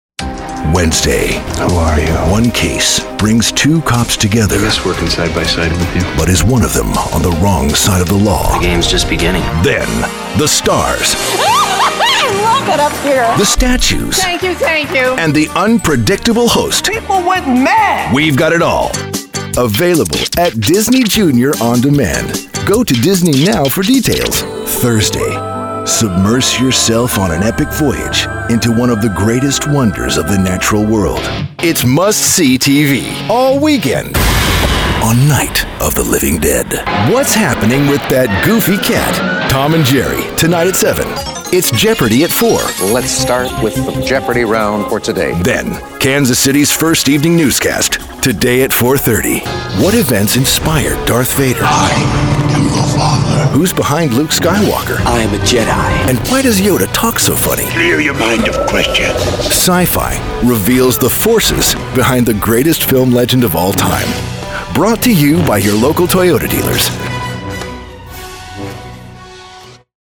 Promo
His rugged baritone is well-traveled, trustworthy, believable and altogether gripping. It can be intimate and inviting, or intense and commanding.